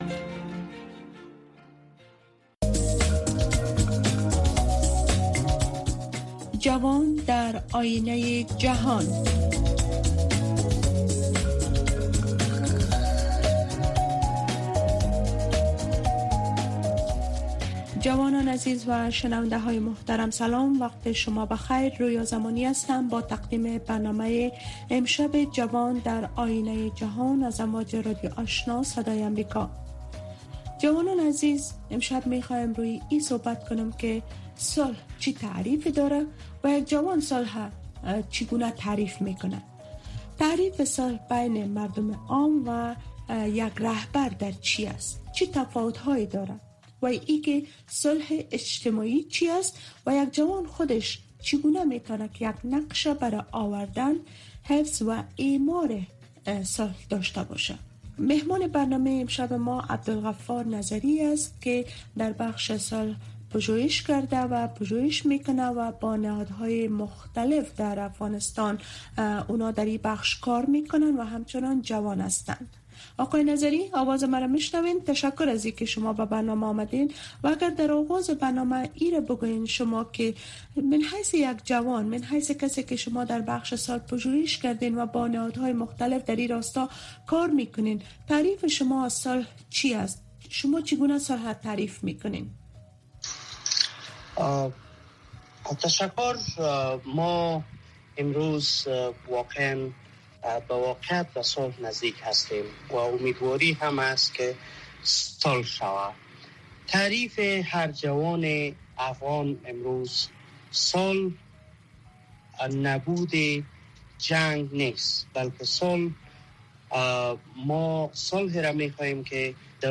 گفت و شنود بحث رادیویی است که در آن موضوعات مهم خبری با حضور تحلیلگران و مقام های حکومت افغانستان به بحث گرفته می شود. گفت و شنود به روزهای سه شنبه و جمعه به ترتیب به مسایل زنان و صحت اختصاص یافته است.